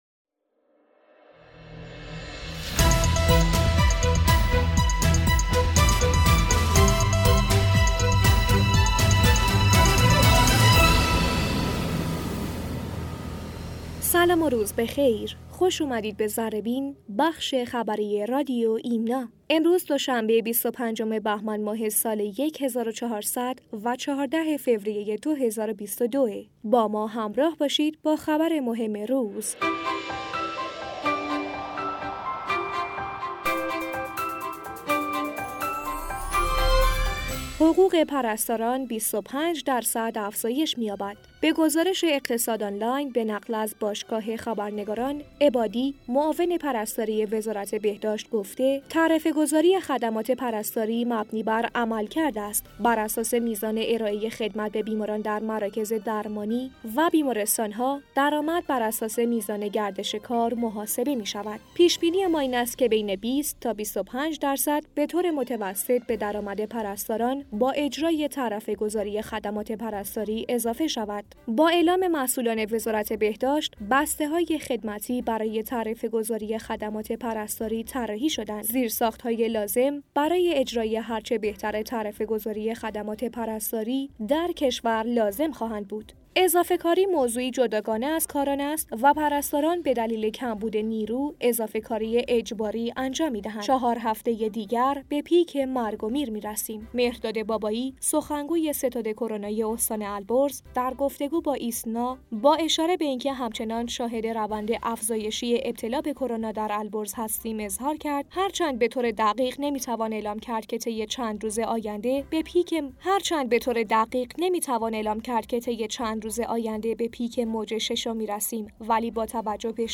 گوینده: